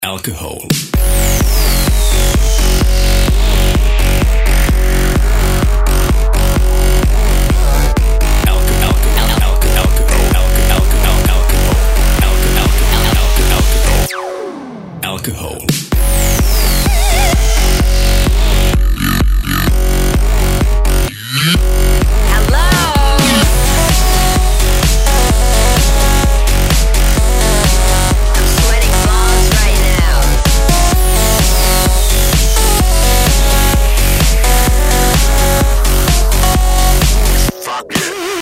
house
качает